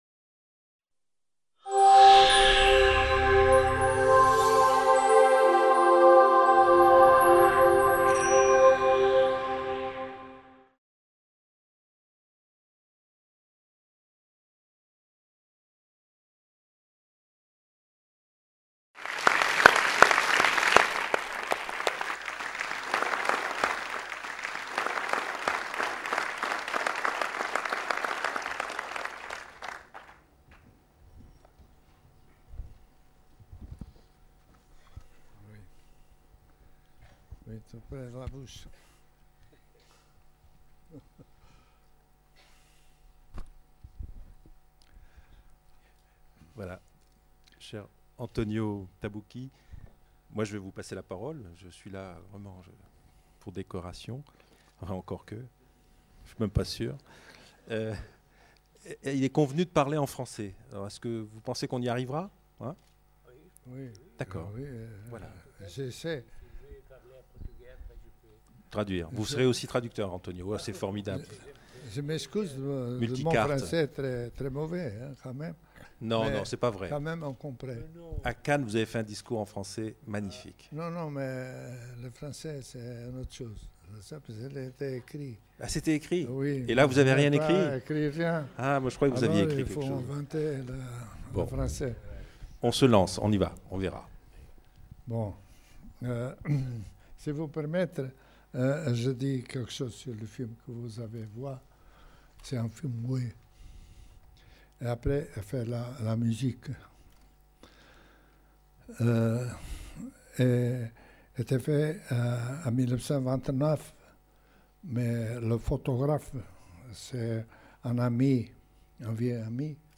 À la suite de la projection du premier court métrage de Manoel de Oliveira, Douro, Faina Fluvial (1929-1931), discussion à bâtons rompus entre le metteur en scène et l’écrivain : la littérature, l’Europe, le cinéma...